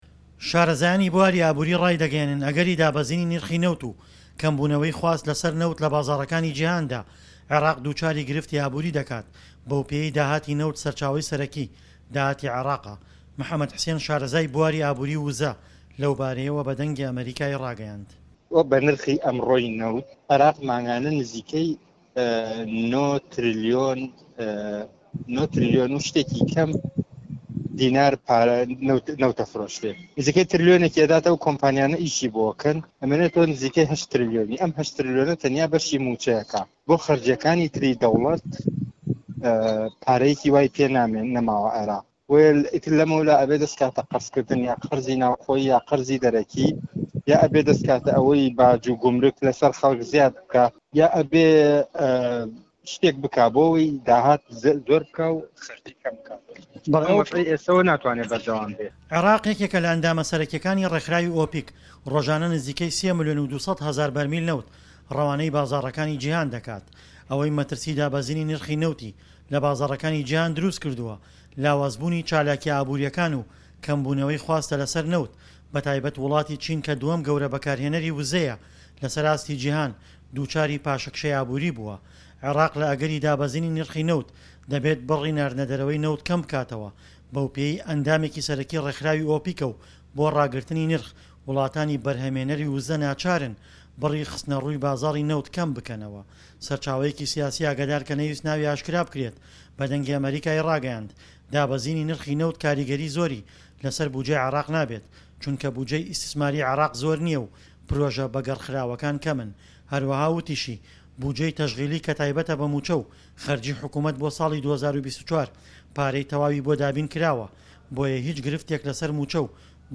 ڕاپۆرتی